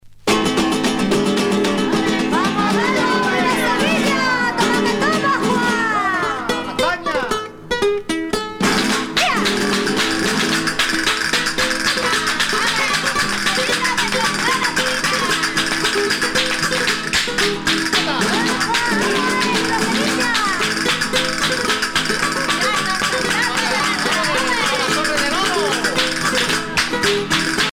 danse : sevillana
Pièce musicale éditée